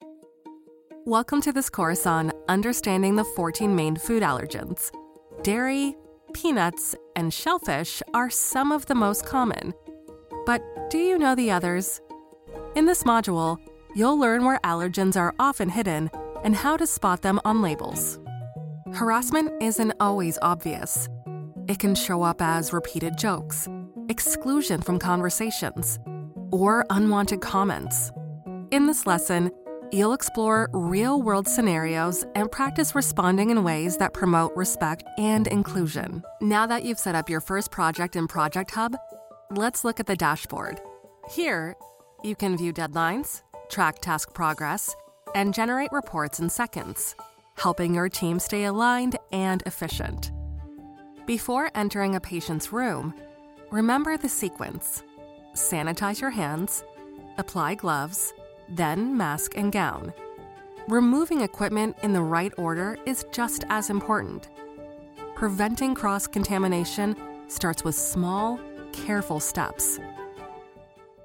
Anglais (Américain)
Commerciale, Naturelle, Cool, Chaude
E-learning